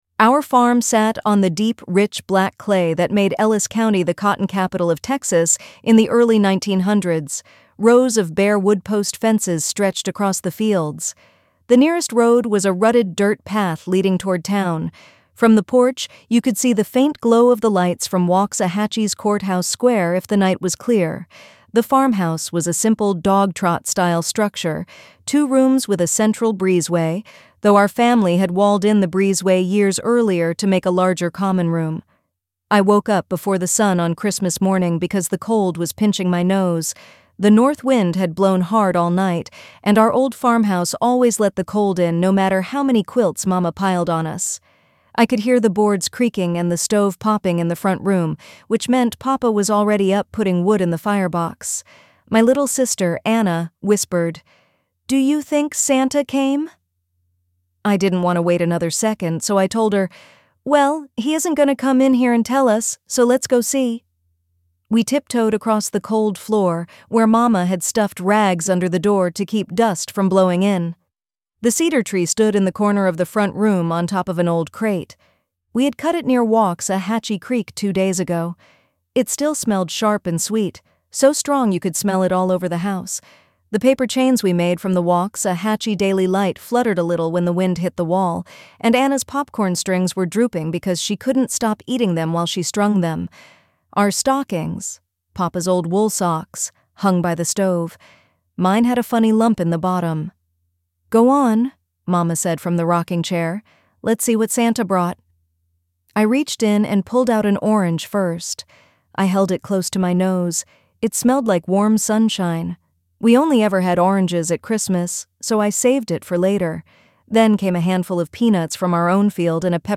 ElevenLabs_My-Ellis-County-Christmas.mp3